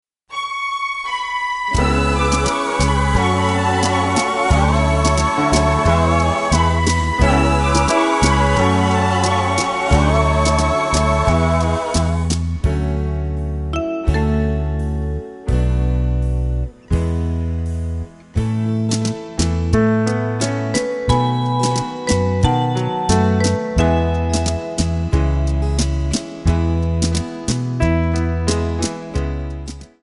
Backing track files: 1960s (842)
Buy With Backing Vocals.